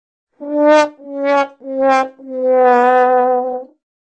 Sound Fail (Fallo) - Aircontrol Italy Srl
Sound-Fail-Fallo.mp3